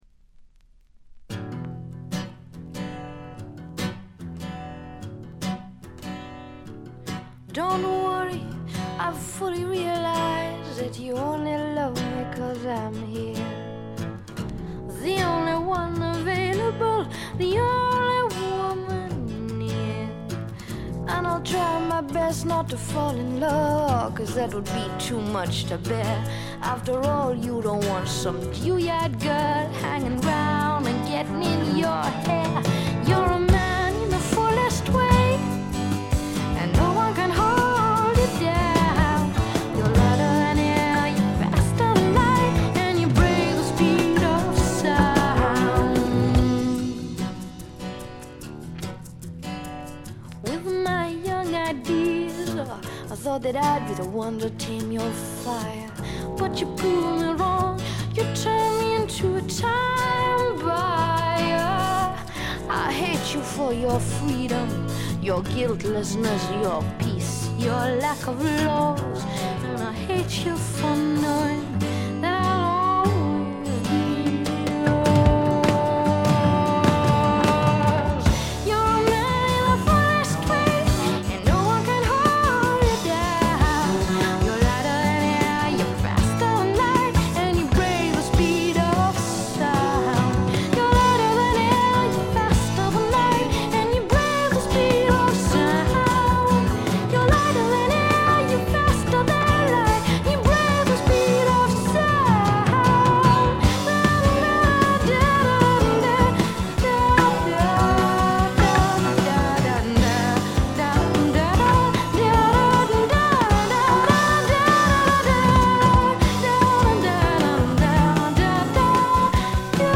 静音部でバックグラウンドノイズ、チリプチ少々、散発的なプツ音少し。
ほとんど弾き語りのような曲が多いのもよいですね。
試聴曲は現品からの取り込み音源です。